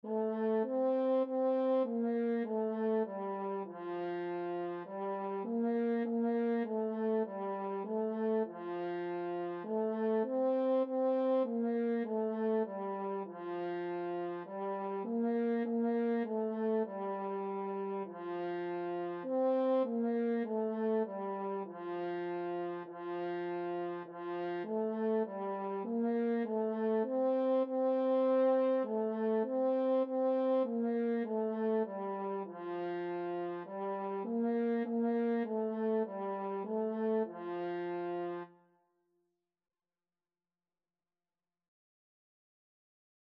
4/4 (View more 4/4 Music)
F4-C5
French Horn  (View more Beginners French Horn Music)
Classical (View more Classical French Horn Music)